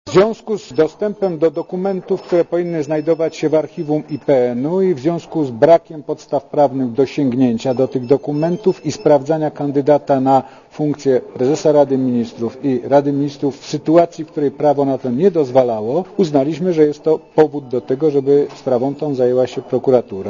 Mówi Zbigniew Wasserman